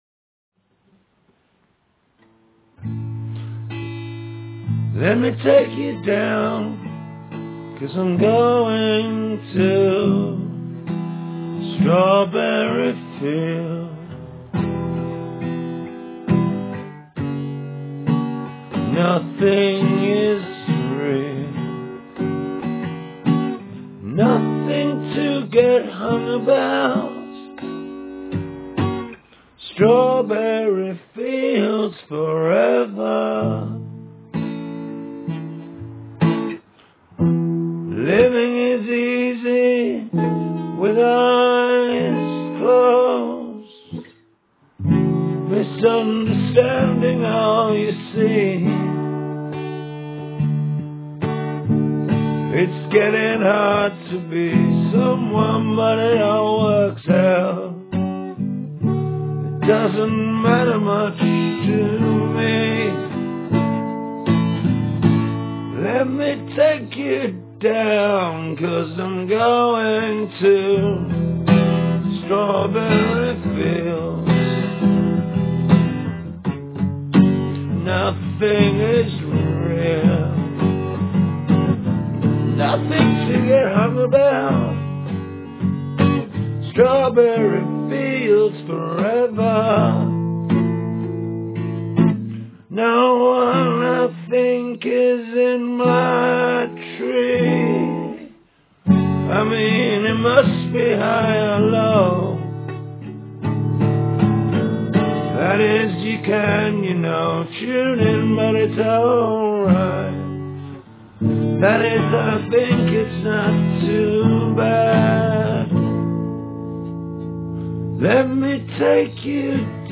one take